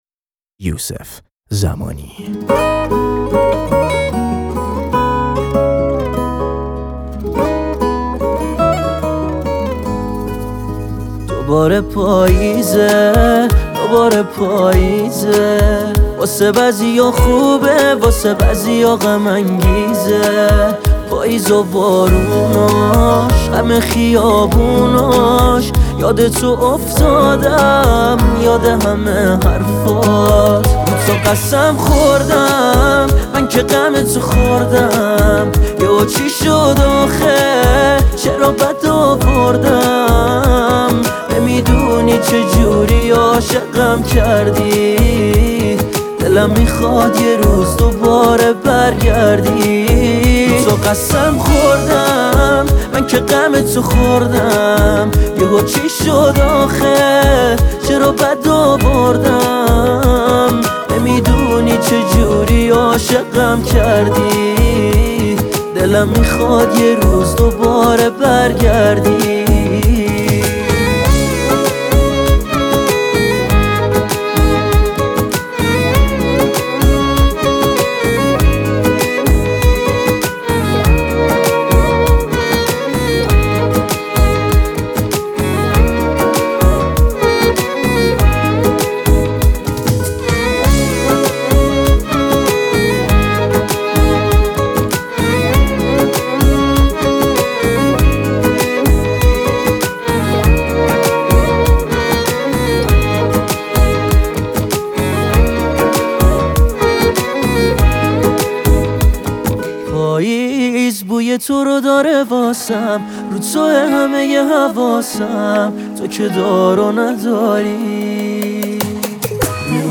دانلود آهنگ پاپ